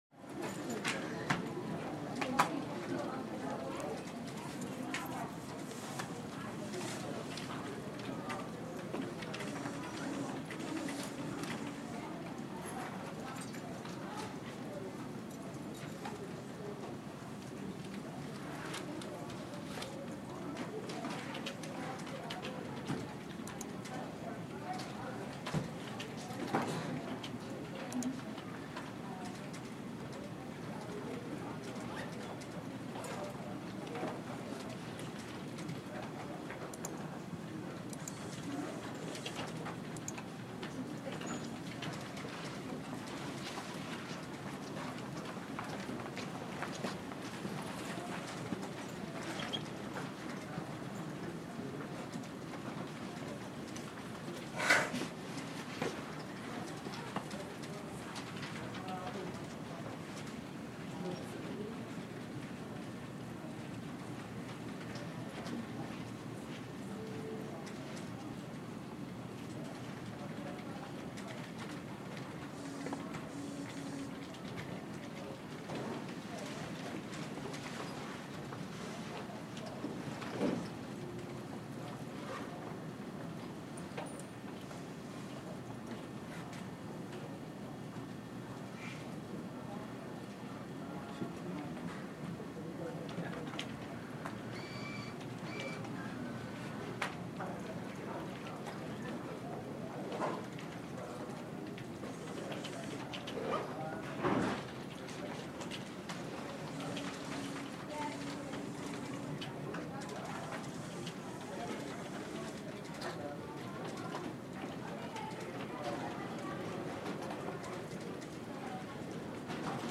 На этой странице собраны звуки библиотеки: тихий шелест страниц, шаги между стеллажами, отдаленные голоса читателей.
Шум книг в университетской библиотеке